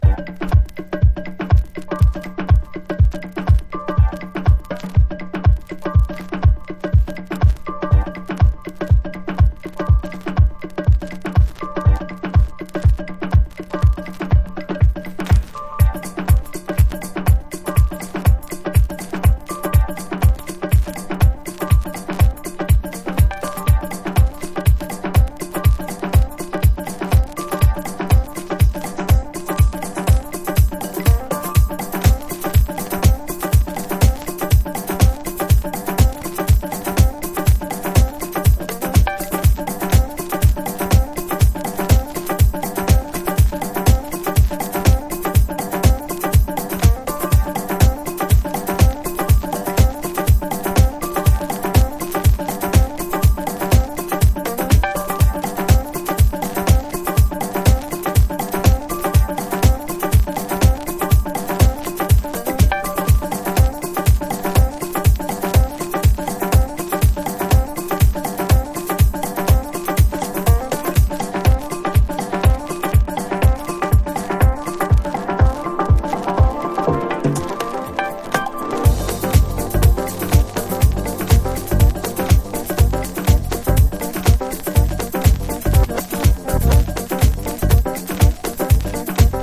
DEEP HOUSE / EARLY HOUSE